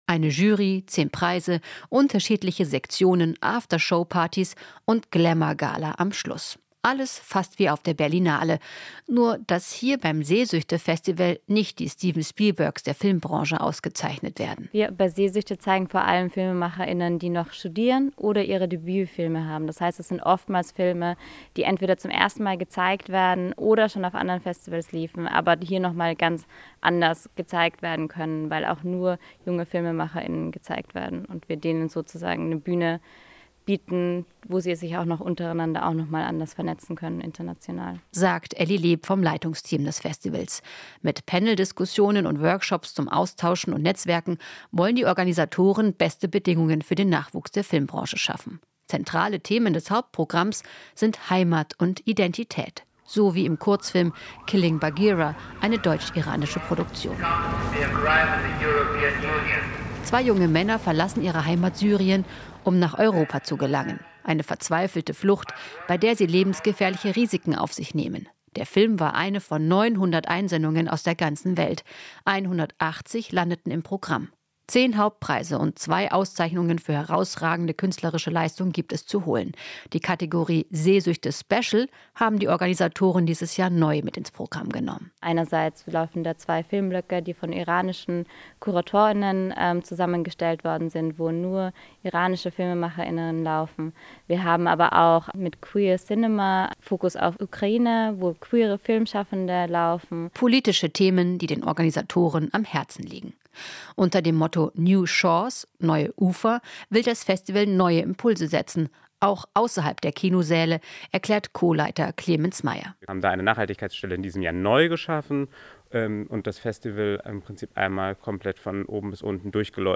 Inforadio Nachrichten, 09.06.2023, 06:20 Uhr - 09.06.2023